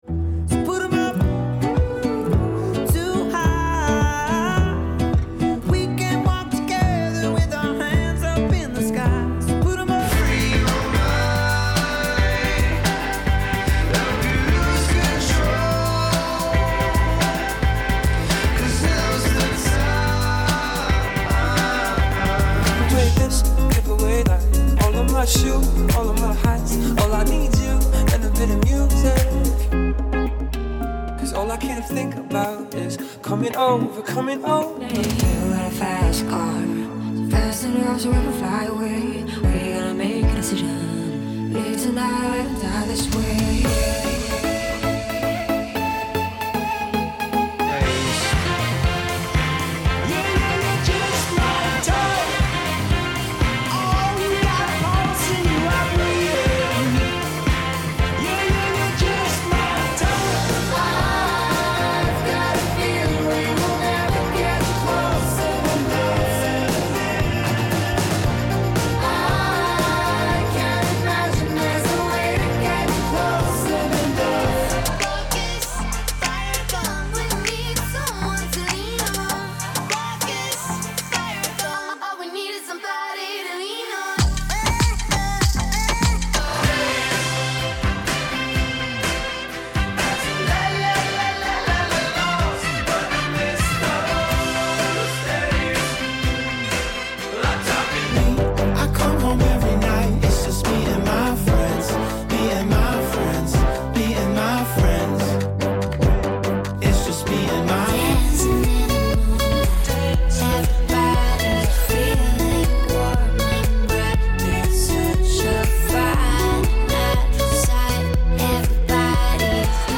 Mid Tempo